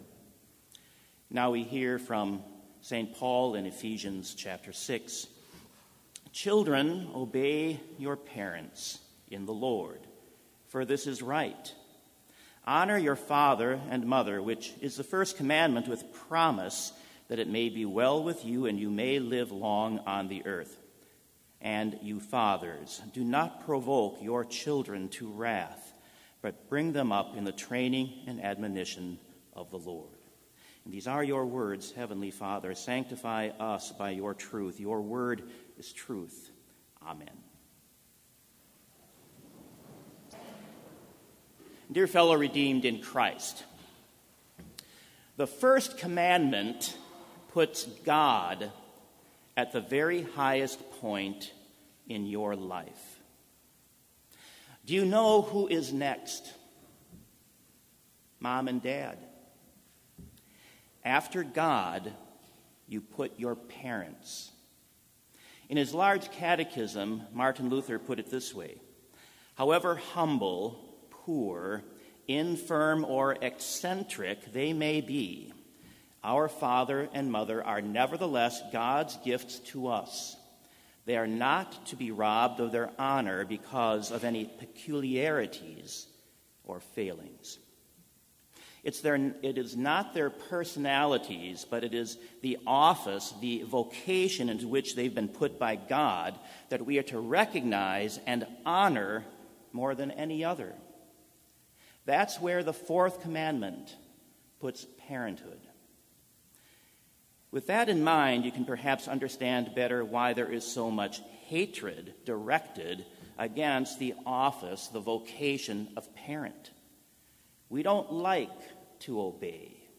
Complete Service
• Prelude
• Devotion
This Chapel Service was held in Trinity Chapel at Bethany Lutheran College on Wednesday, January 16, 2019, at 10 a.m. Page and hymn numbers are from the Evangelical Lutheran Hymnary.